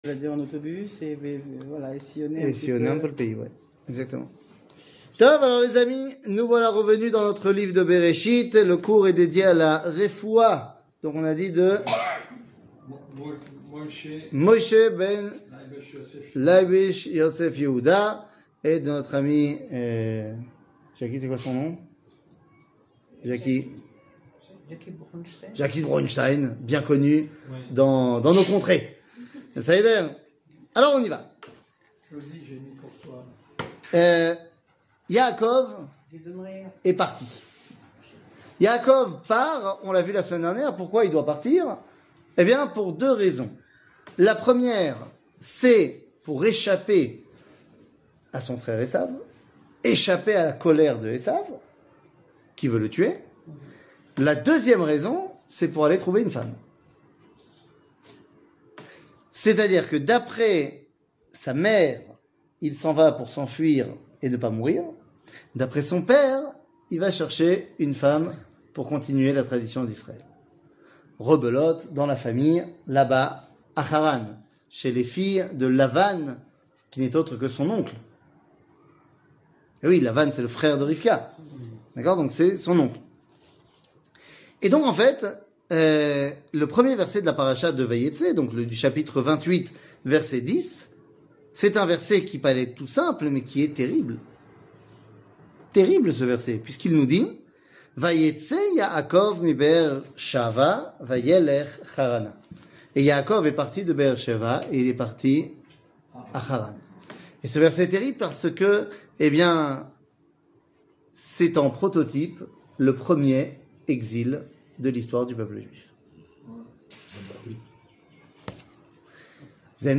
קטגוריה d 00:44:01 d שיעור מ 06 יולי 2022 44MIN הורדה בקובץ אודיו MP3
שיעורים קצרים